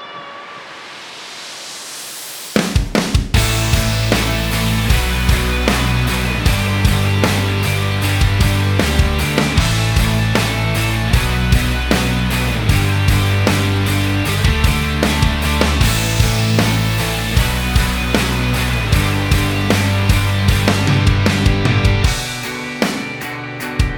Minus Main Guitars Pop (2010s) 3:39 Buy £1.50